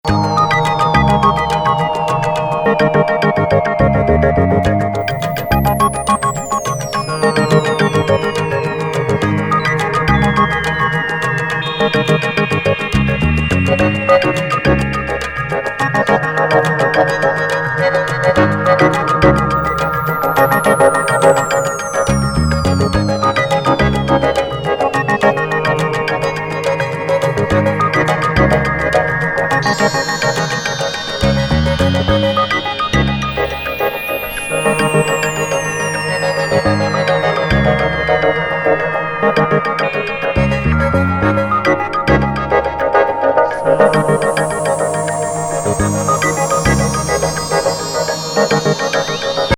傑作2ND.絶対にダウナーに引きずり込まないトランシー・アンビエンスB1